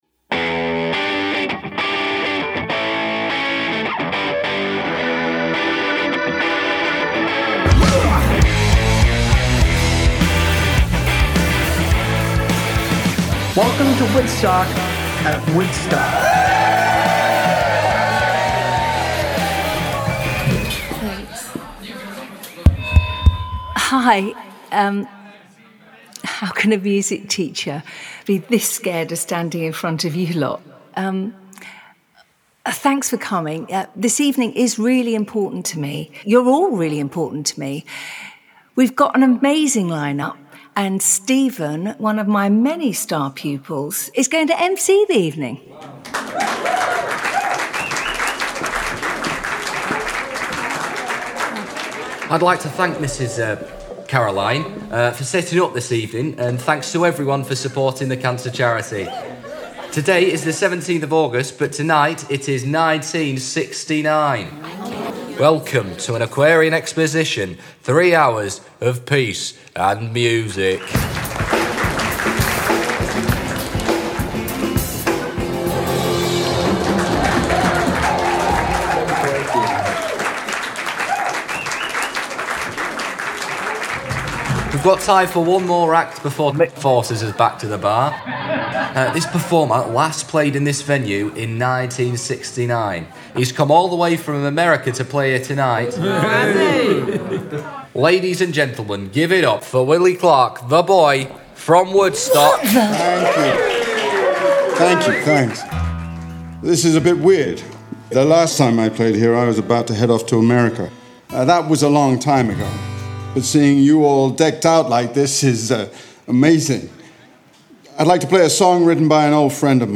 PLAYING WOODSTOCK – Audio Play
This was originally recorded as a table read, in a room with less-than-ideal acoustics. A narrator read the scenes and action lines. The audio was all comped from 2 takes, edited, and all narration, taken out. Some additional dialogue was then recorded remotely and matched in. Music, Foley and SFX were then added to complete a very ‘visual’ audio play.